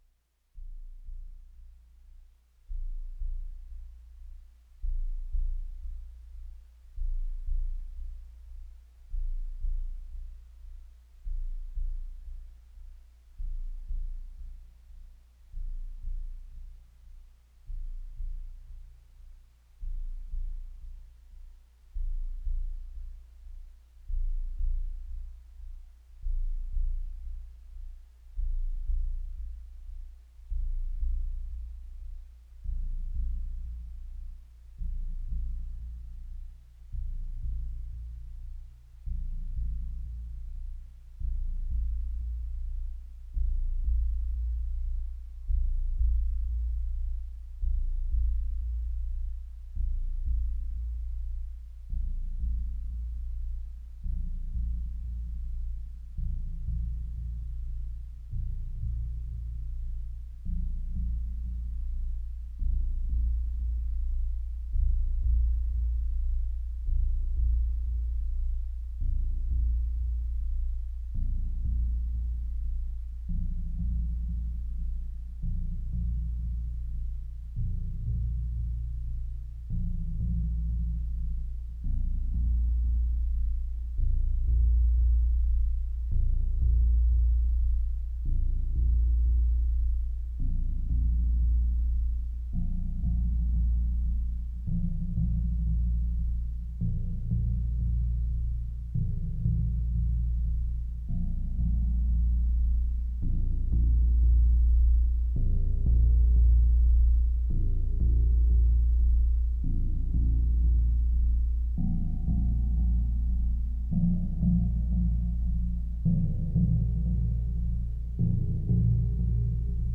• Uniquement le A-100 version du moment.
• Type nuage complexe. Une voix de bourdon et une voix avec le 1630
• Les effets sont le n°15 de la table Behringer Xenyx X1622.
• Utilisation du FEG de Rides in the storm en mode loop lente sur le filtre A120.
• Format : ogg (stéréo)